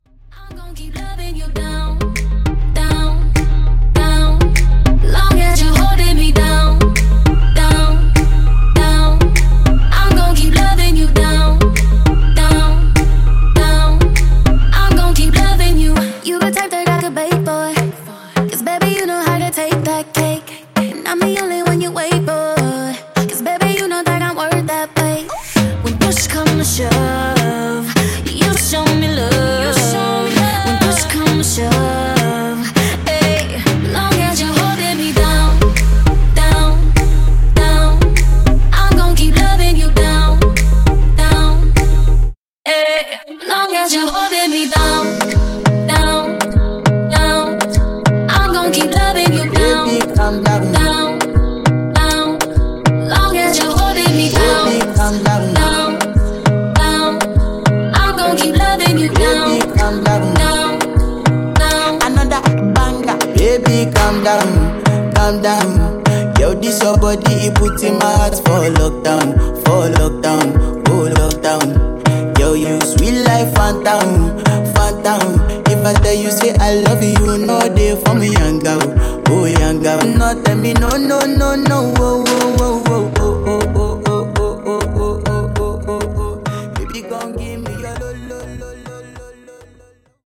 WordPlay Segue Edit)Date Added